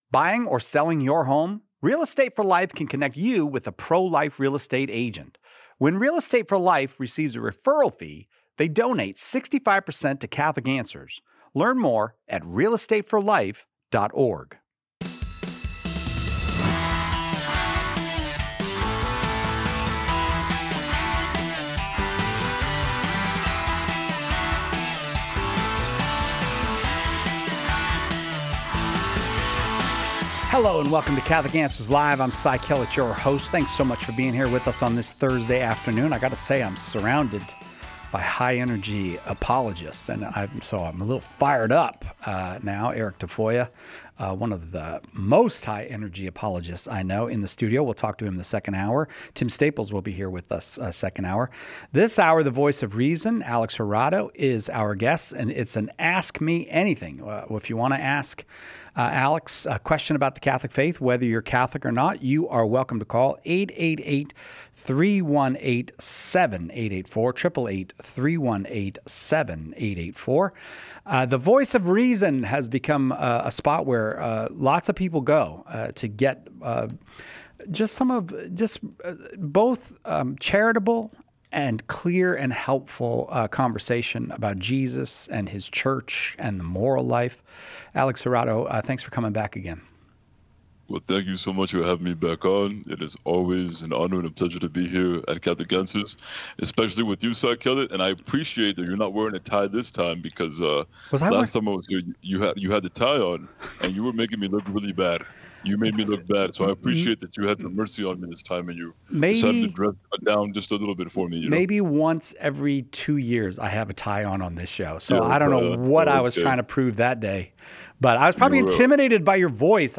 tackles thoughtful and challenging questions from callers exploring Catholic teaching and doctrine. Topics include the true meaning of the word anathema , biblical support for confession to a priest, and how to explain the seal of confession in difficult moral situations.